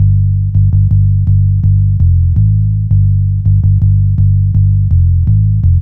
06Throbber 165bpm Dm.wav